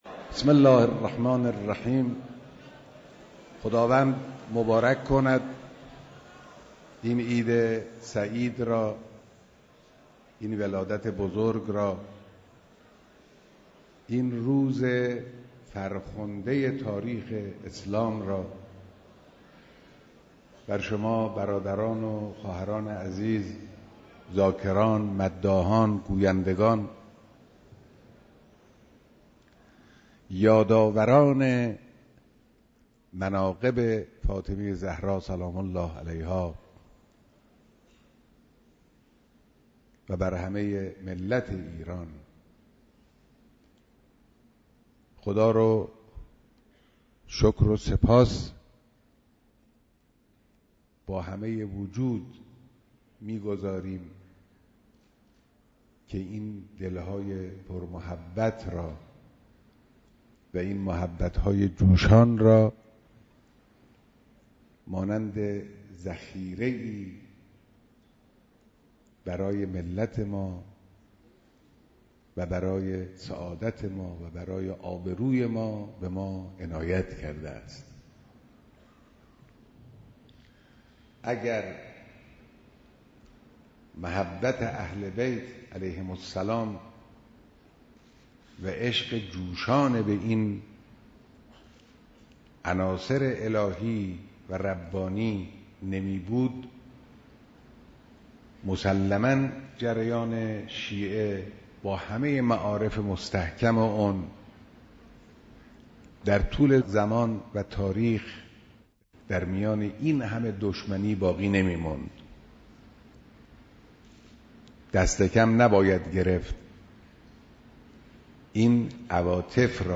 دیدار جمعی از شاعران، ذاکرین و مداحان اهل بیت (علیهم السلام)